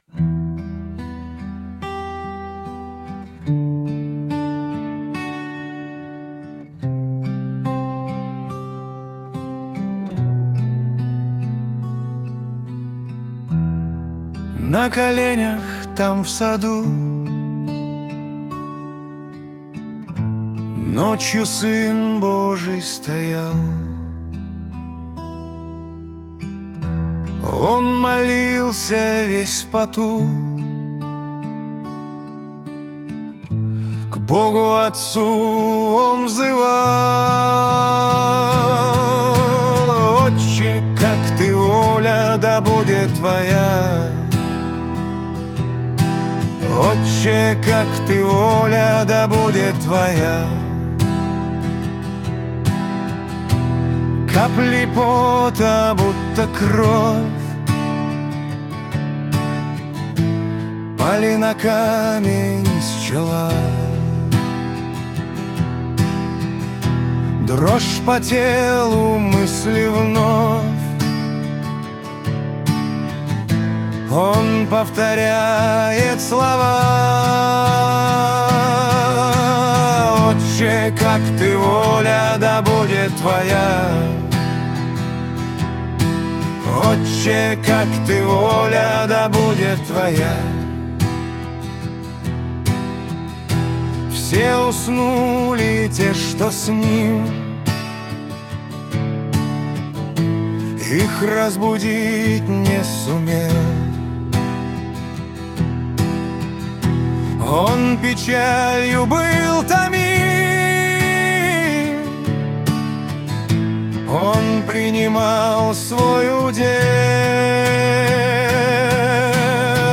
песня ai
54 просмотра 308 прослушиваний 8 скачиваний BPM: 76